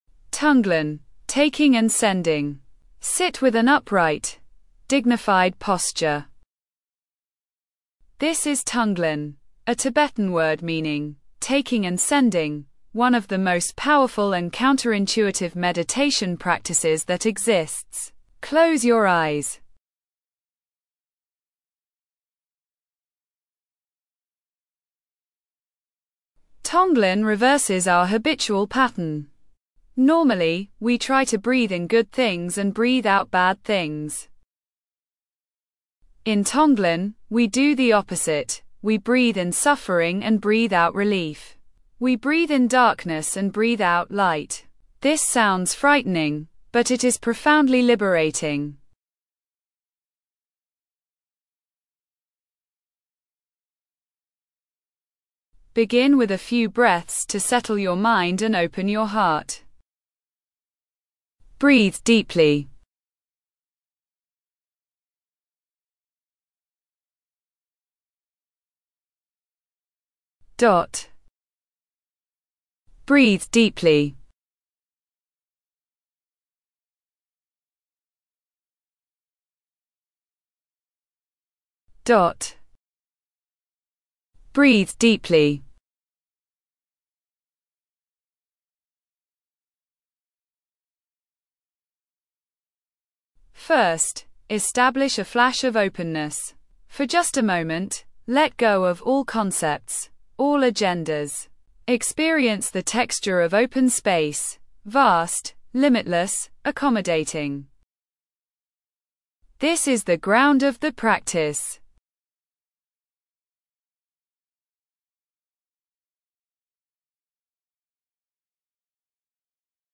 stress relief 18 min advanced